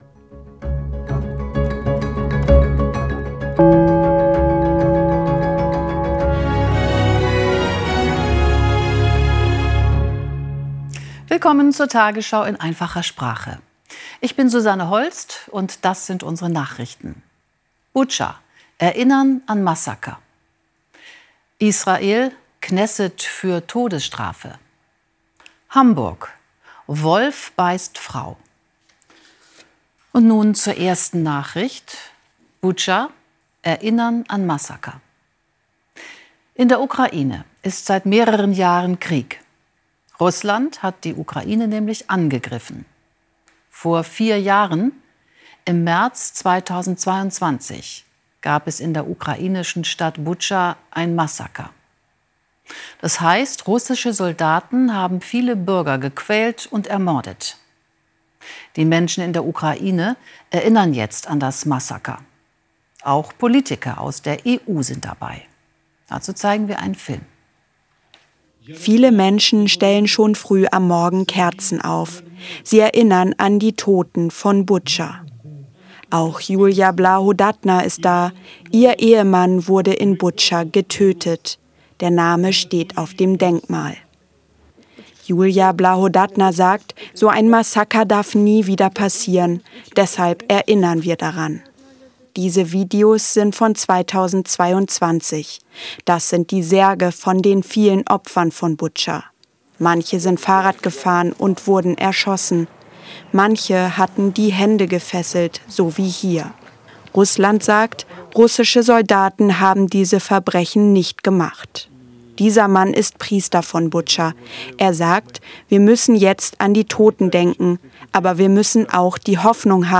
Bildung , Nachrichten